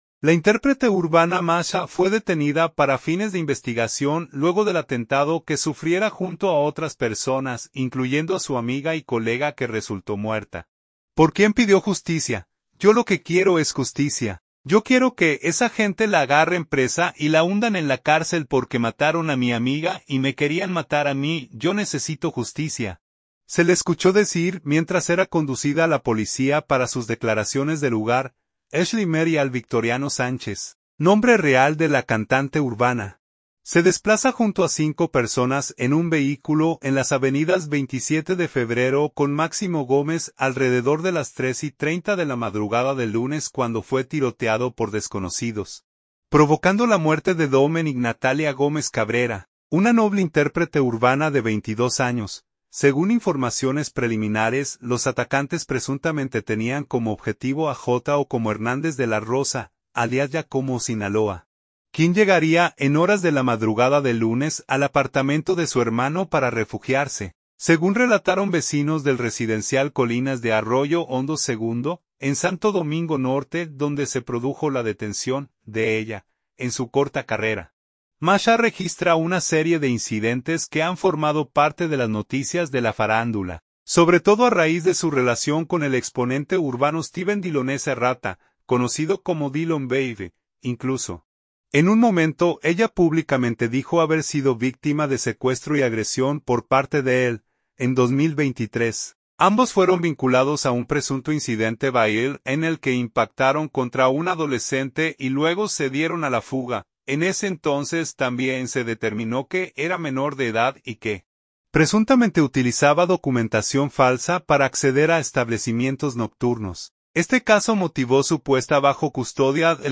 "Yo lo que quiero es justicia, yo quiero que esa gente la agarren presa y la hundan en la cárcel porque mataron a mi amiga y me querían matar a mí, yo necesito justicia", se le escuchó decir mientras era conducida a la Policía para sus declaraciones de lugar.